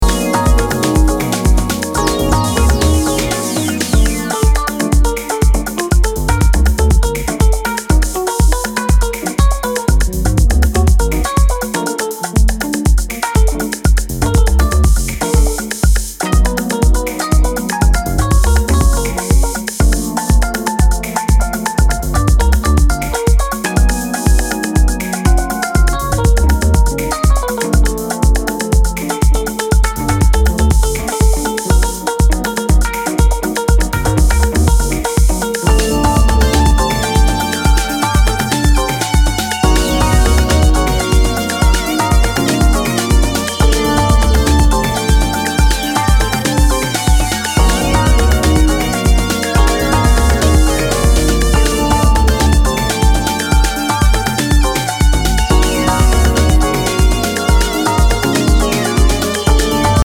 ブロークンビーツ的なドラミングにファンキーなベース・ライン
開放的な空気感を纏った爽やかで抜けのあるジャズファンク・ハウス〜ダウンテンポを繰り広げています。